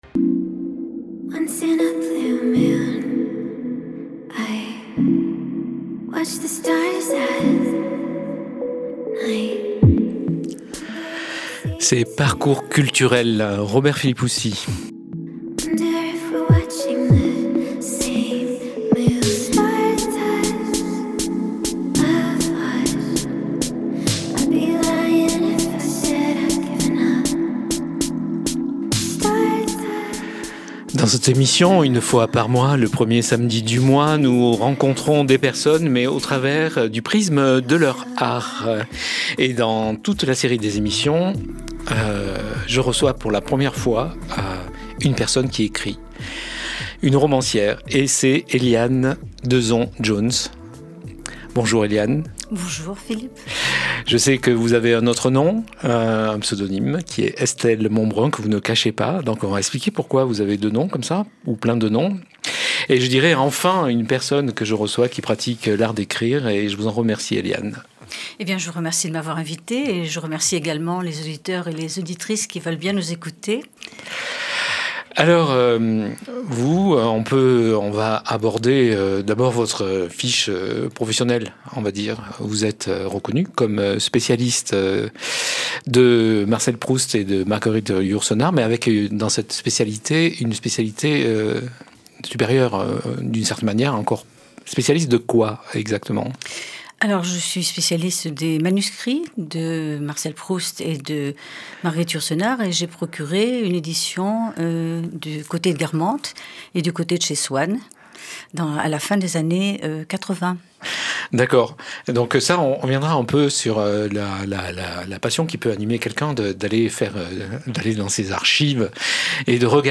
l'entretien que j'ai eu sur Fréquence Protestante le 4 novembre 2023